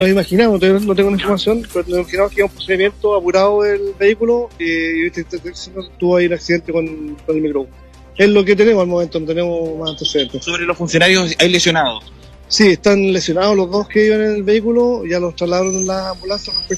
El jefe de la Octava Zona de Carabineros, el general Renzo Micono, quien se encontraba en una actividad protocolar en la comuna de Lota, afirmó que presumiblemente los efectivos iban a un procedimiento.